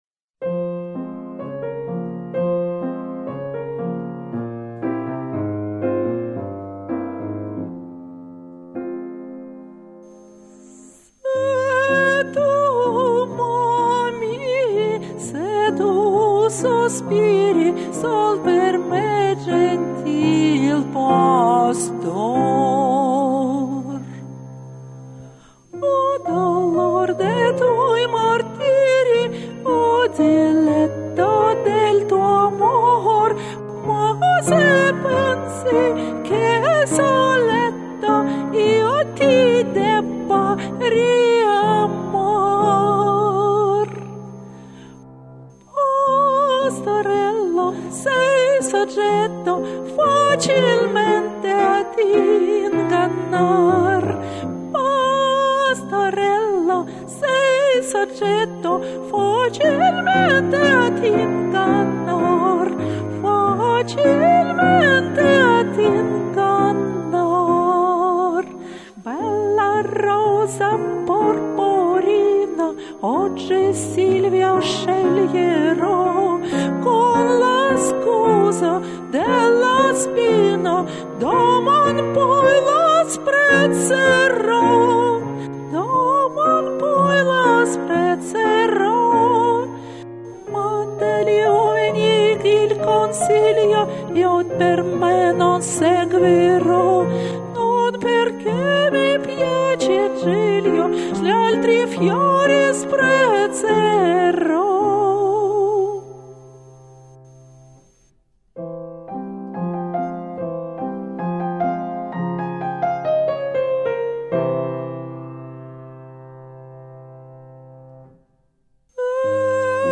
сопрано и меццо-сопрано - от учениц до великих
Комментарий соперника: Поскольку это никак не опера, а всего лишь камерная ария (для малых залов), рискну принять вызов.